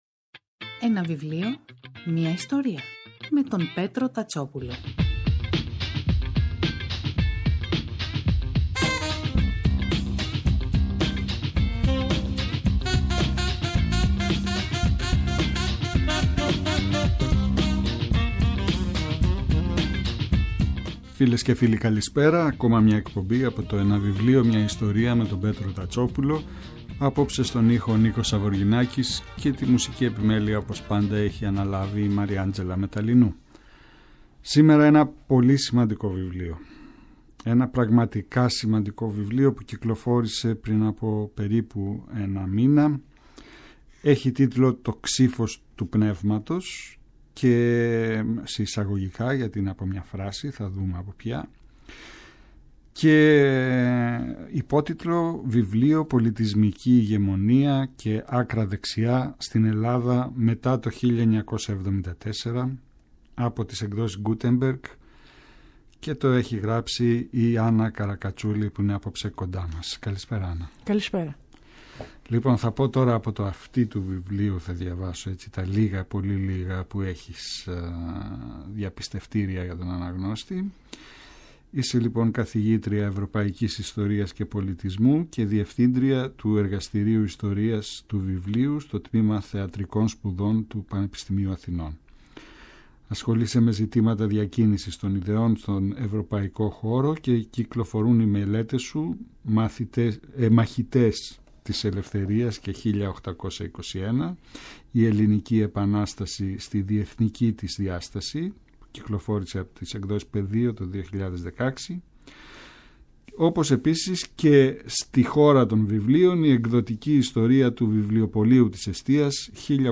Κάθε Σάββατο και Κυριακή, στις 5 το απόγευμα στο Πρώτο Πρόγραμμα της Ελληνικής Ραδιοφωνίας ο Πέτρος Τατσόπουλος , παρουσιάζει ένα συγγραφικό έργο, με έμφαση στην τρέχουσα εκδοτική παραγωγή, αλλά και παλαιότερες εκδόσεις.